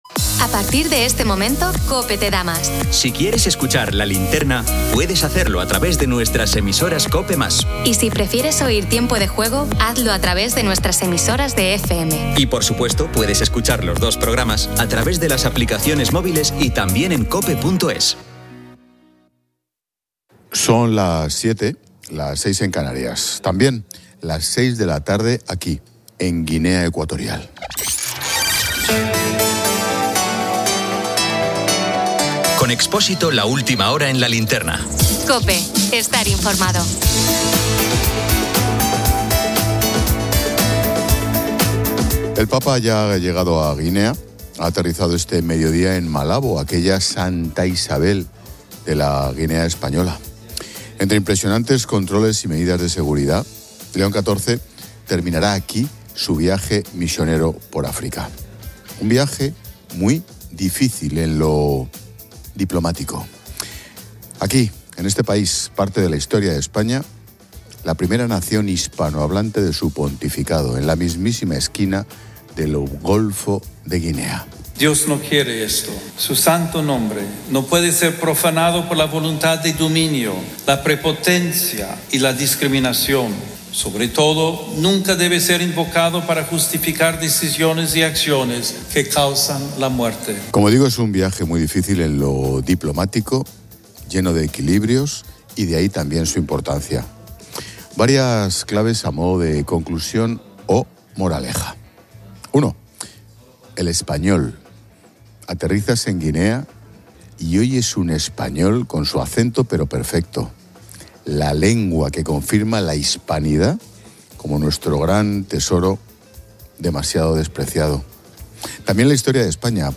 El programa destaca la visita del Papa a Guinea Ecuatorial, un viaje complejo que subraya la hispanidad, el catolicismo y la riqueza del país, enfatizando la migración desde su origen y la necesidad de políticas a contracorriente. Se entrevista a familias misioneras españolas que comparten su experiencia y los desafíos de su misión.